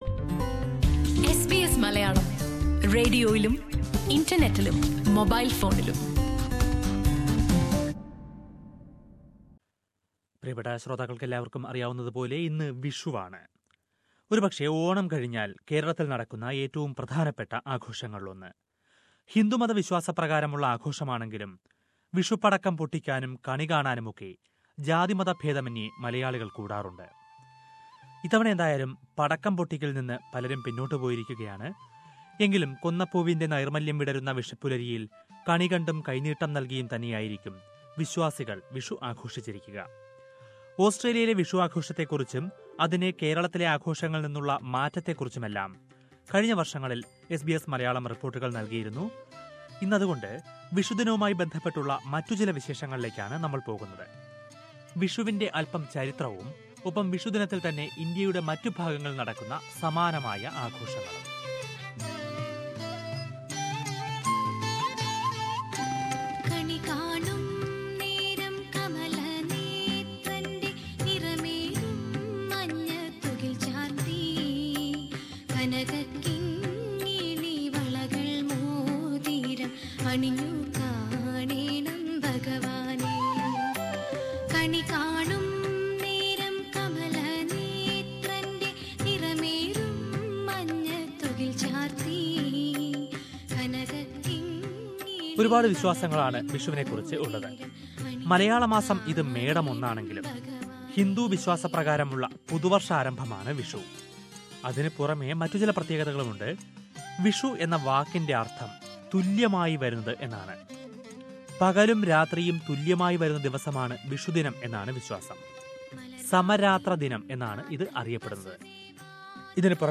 When Malayalees celebrate Vishu on 14th of April, people from other Indian states and neighbouring countries also celebrate new year - in different names. Let us listen to a report on that.